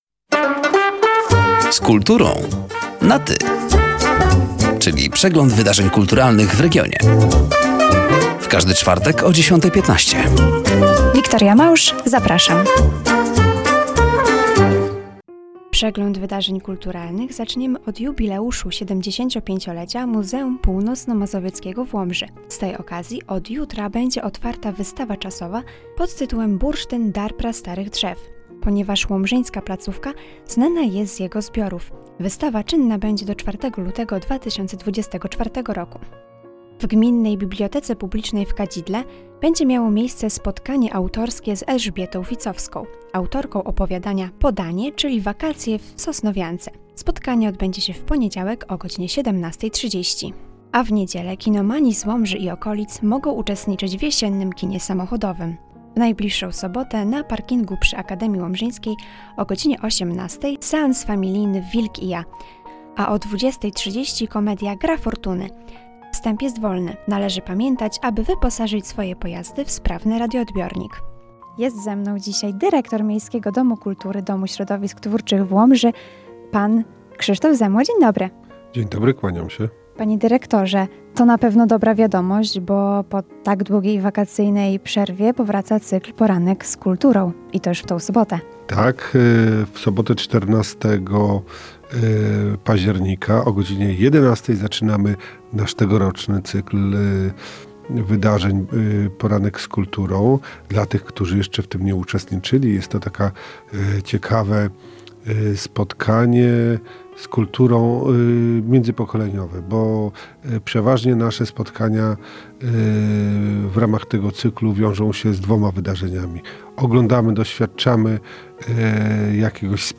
Zapraszamy do przeglądu wydarzeń kulturalnych i wysłuchania rozmowy.